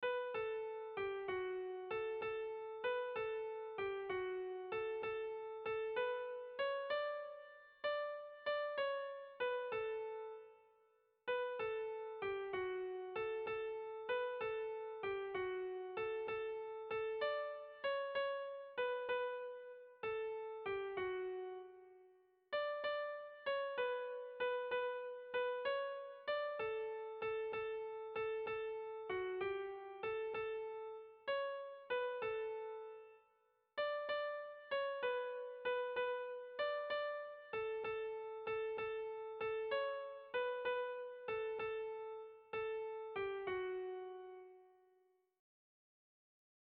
Irrizkoa
Eibar < Debabarrena < Gipuzkoa < Euskal Herria
Zortziko handia (hg) / Lau puntuko handia (ip)
A1A2B1B2